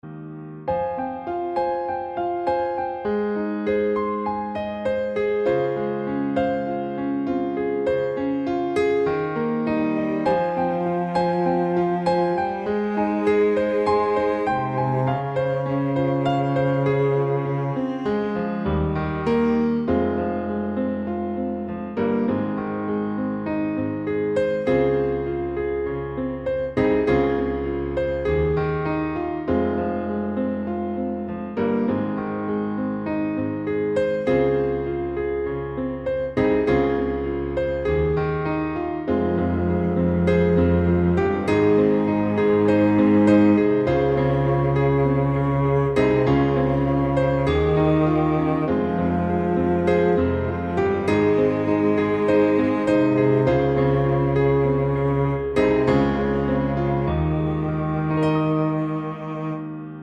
Down 2 Semitones